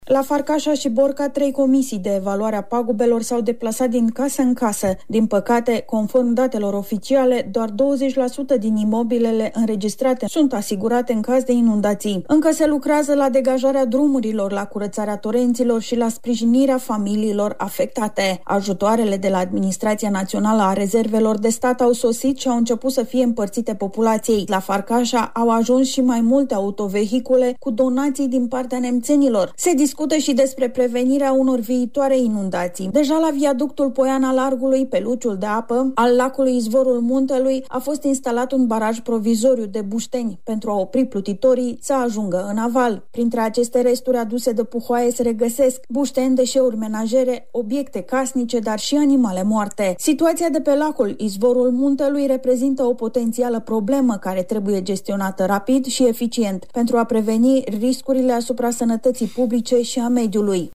Corespondenta noastră